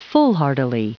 Prononciation du mot foolhardily en anglais (fichier audio)
Prononciation du mot : foolhardily